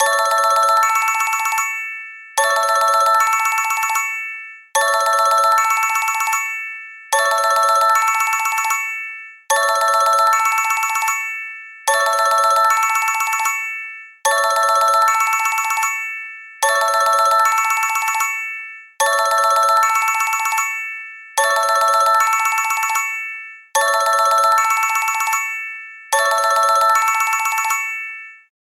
알림음(효과음) + 벨소리
알림음 8_Xylophone.ogg